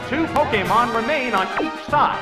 Category: Games   Right: Personal
Tags: pokemon stadium announcer butts